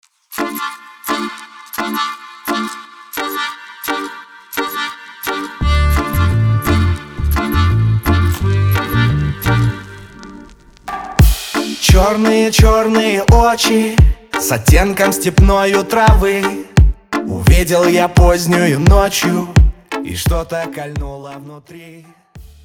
аккордеон
инструментальные
Классный аккордеон